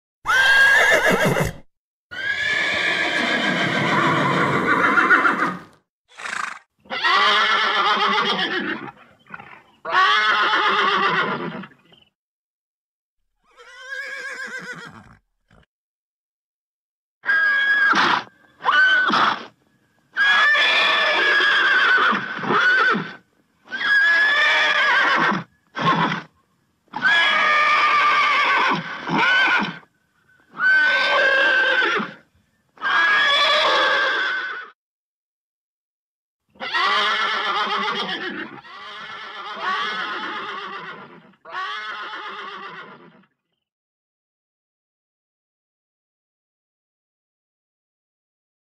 Horse-Neighing-sound-effect-2017.mp3